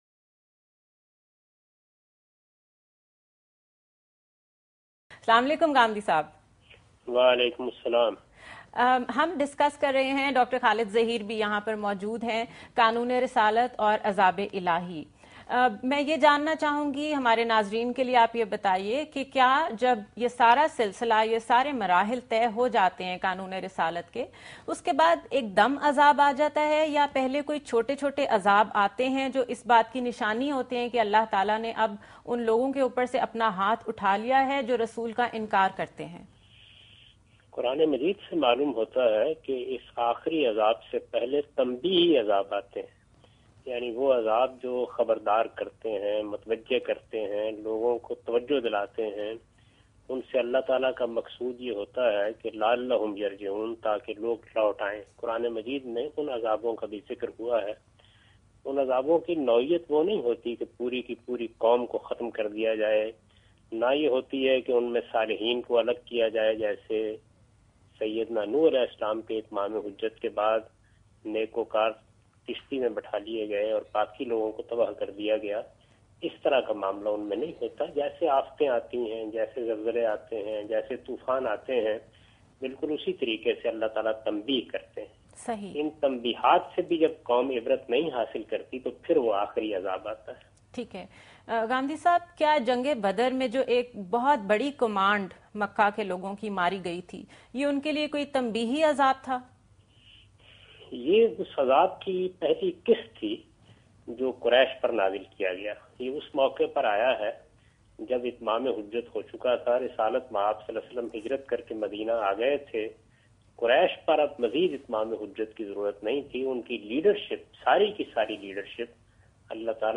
Category: TV Programs / Dunya News / Questions_Answers /